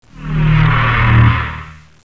文件名：fjs，保存类型：WAV 音频文件—声音格式：22 KHz 8 位 单声道。
飞机轰轰声：fjs (